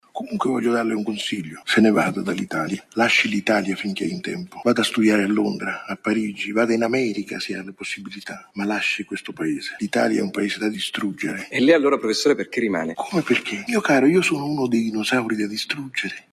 Avete appena ascoltato un estratto de “La meglio gioventù”, un film del 2003, diretto da Marco Tullio Giordana. Il dialogo tra il giovane studente Nicola e il professore barone universitario continua a offrici uno spietato spaccato del nostro Paese.